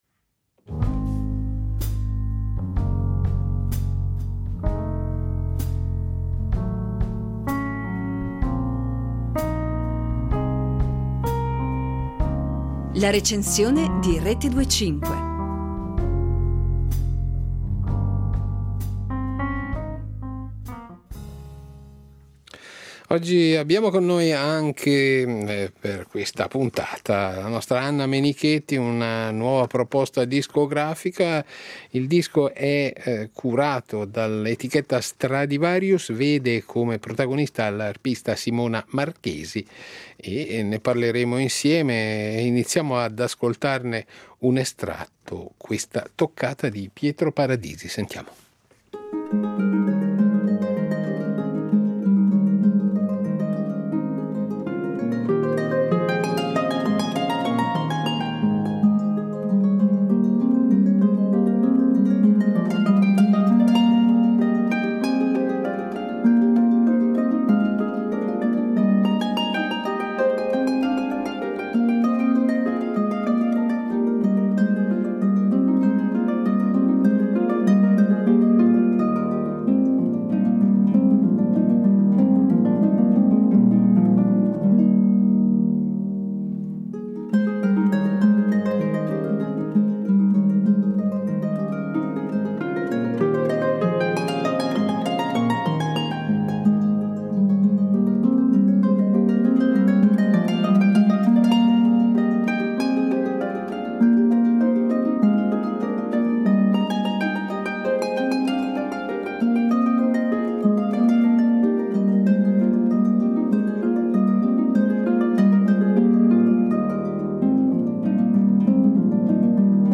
le delicatissime risonanze dell'arpa